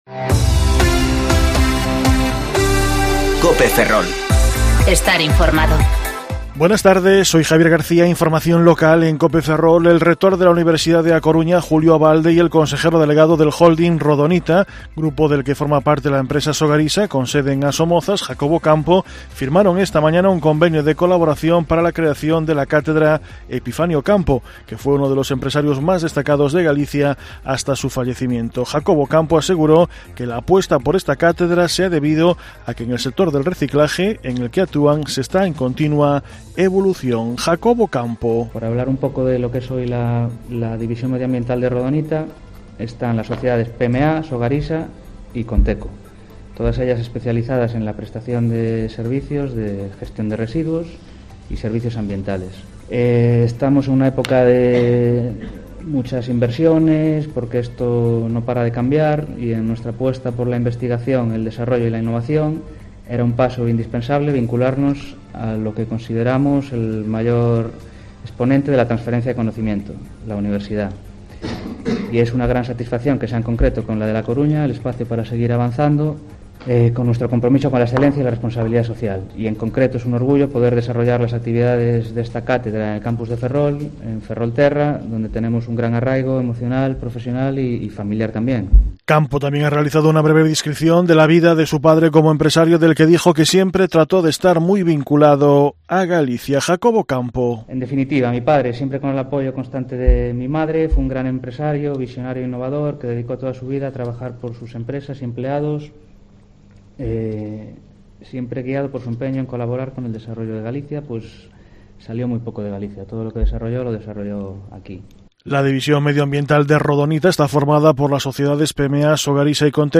Informativo Mediodía Cope Ferrol 14/11/2019 (De 14.20 a 14.30 horas)